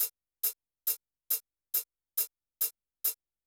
BD HH     -L.wav